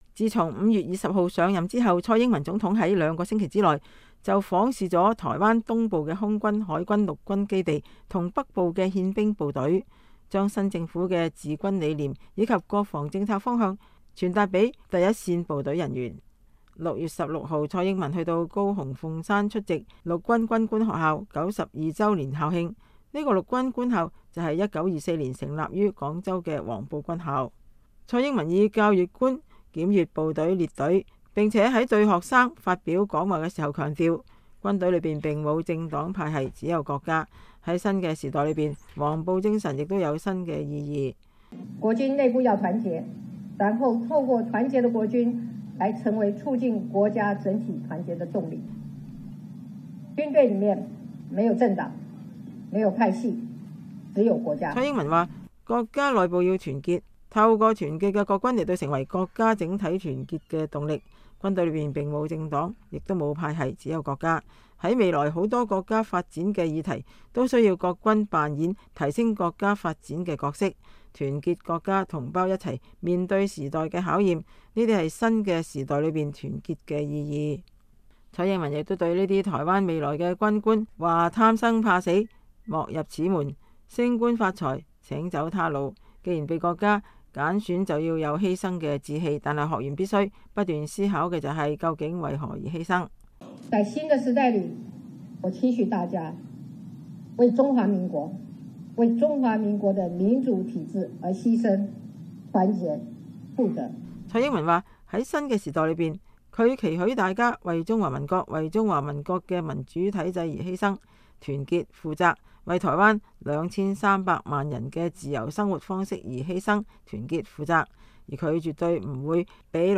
蔡英文以校閱官檢閱部隊列隊，並且在對學生們發表講話時強調，軍隊裡沒有政黨派系，只有國家，在新的時代裡，黃埔精神也有新的意義。